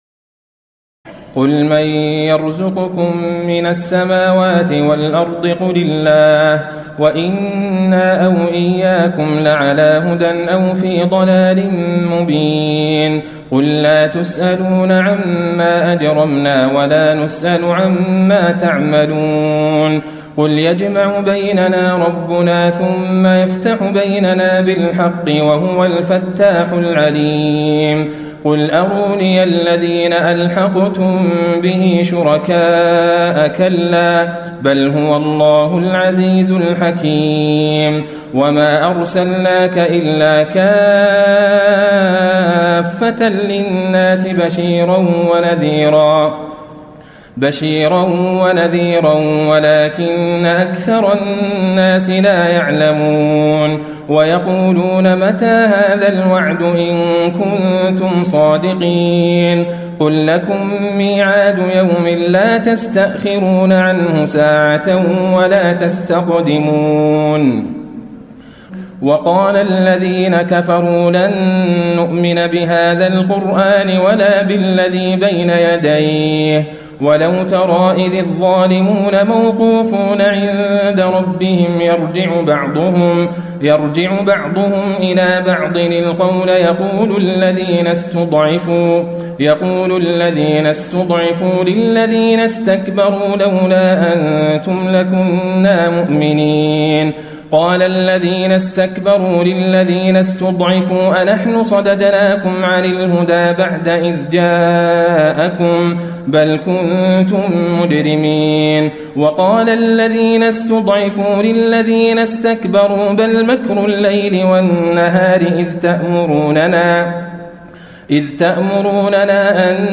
تراويح ليلة 21 رمضان 1434هـ من سور سبأ (24-54) وفاطر و يس (1-27) Taraweeh 21 st night Ramadan 1434H from Surah Saba and Faatir and Yaseen > تراويح الحرم النبوي عام 1434 🕌 > التراويح - تلاوات الحرمين